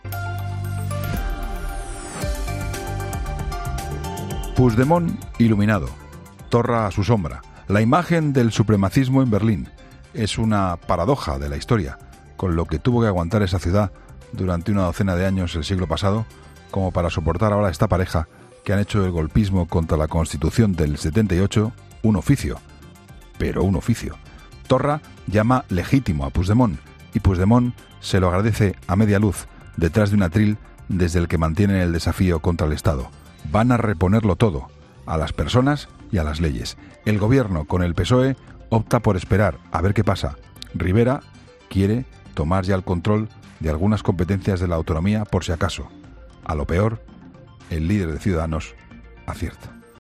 AUDIO: Escucha el comentario del director de 'La Linterna', Juan Pablo Colmenarejo, en 'Herrera en COPE'